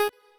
left-synth_melody02.ogg